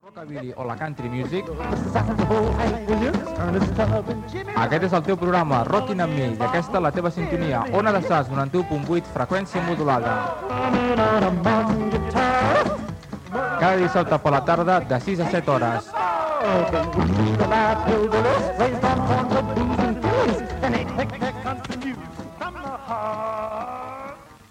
Identificació del programa i de l'emissora.
Musical